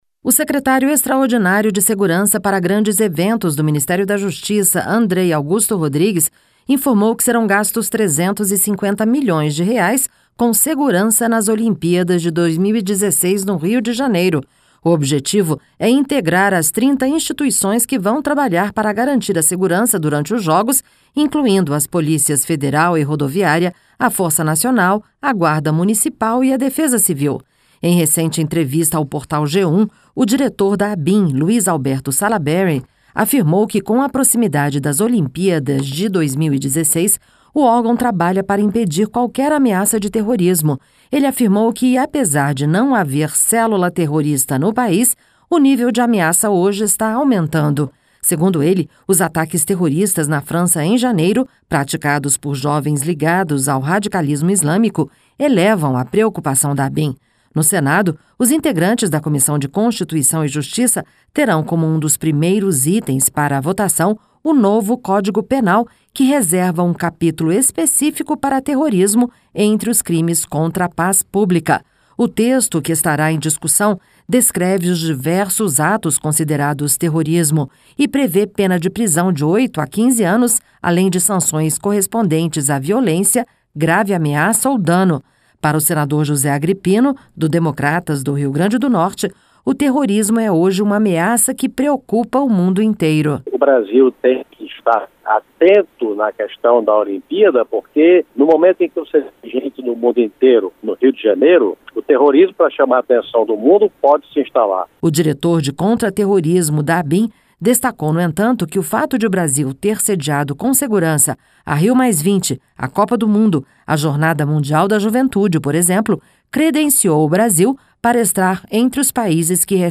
Para o senador José Agripino, do Democratas do Rio Grande do Norte, o terrorismo é hoje uma ameaça que preocupa o mundo inteiro: (AGRIPINO) Brasil